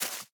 Minecraft Version Minecraft Version latest Latest Release | Latest Snapshot latest / assets / minecraft / sounds / block / cherry_leaves / break4.ogg Compare With Compare With Latest Release | Latest Snapshot